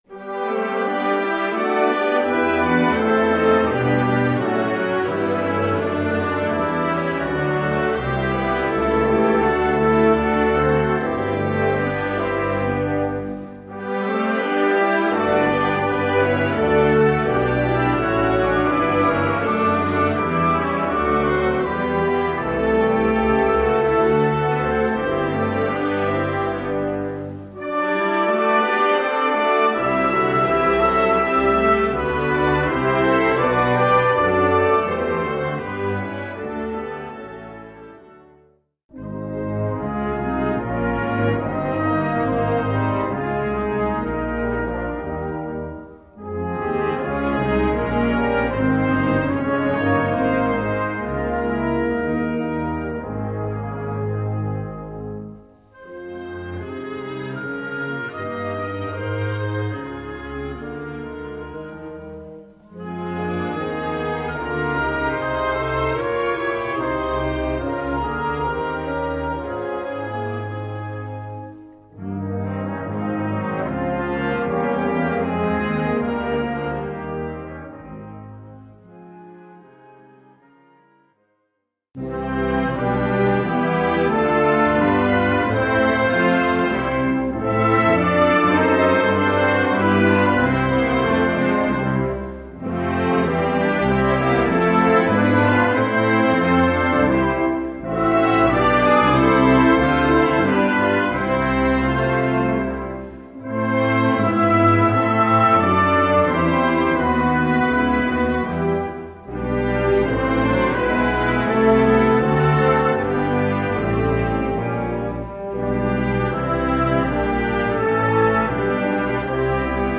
Gattung: Choräle für Blasorchester
Besetzung: Blasorchester